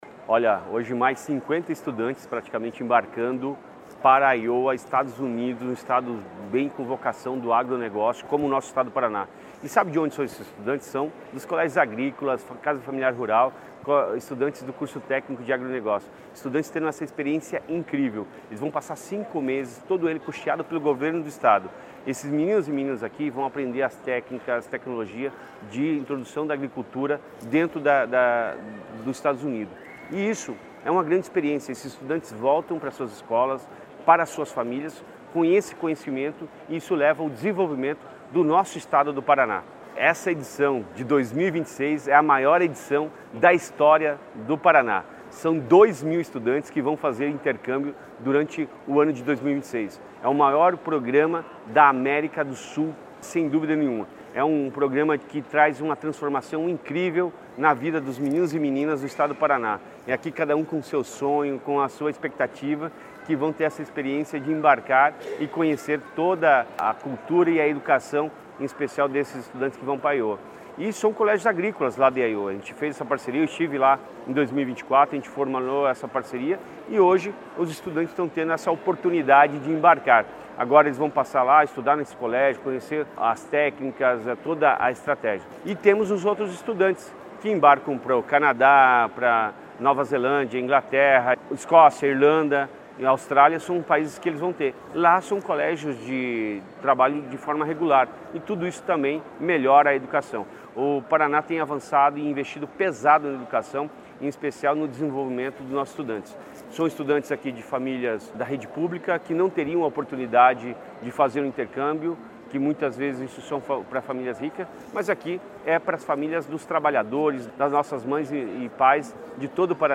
Sonora do secretário da Educação, Roni Miranda, sobre o embarque de 46 estudantes do Ganhando o Mundo Agrícola para os Estados Unidos